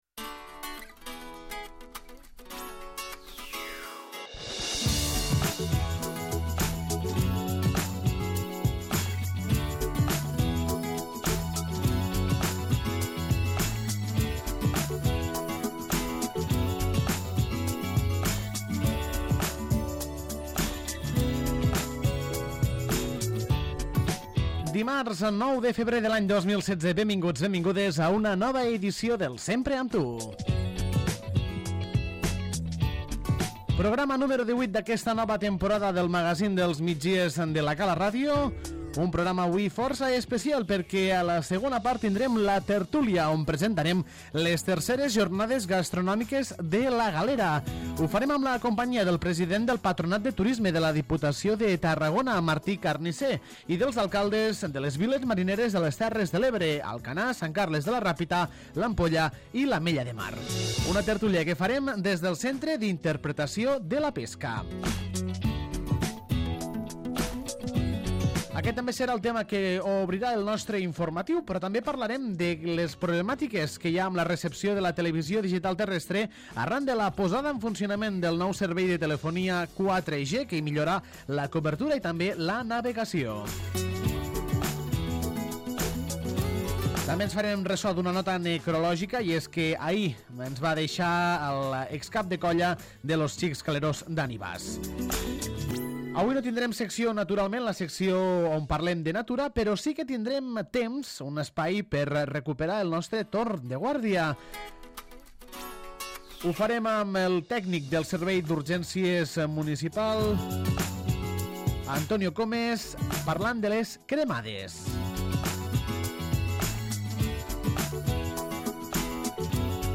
En l'edició de dimarts, 9 de febrer de 2016, del magazín dels migdies de La Cala Ràdio, el Sempre amb tu, ens hem desplaçat al Centre d'Interpretació de la Pesca per compartir una tertúlia amb el president del Patronat de Turisme de la Diputació de Tarragona i els alcaldes de les Viles Marineres parlant de les III Jornades Gastronòmiques de la Galera.